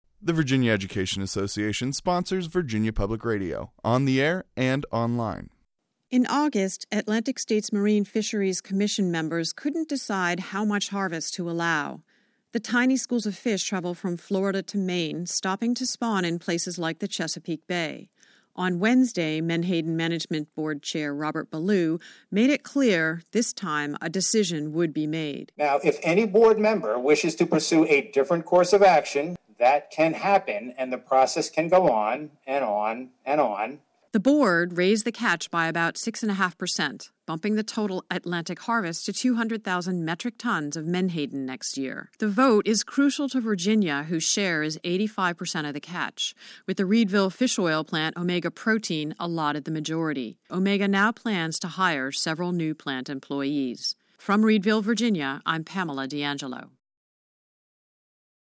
vpr-menhaden-wrap-fixed.mp3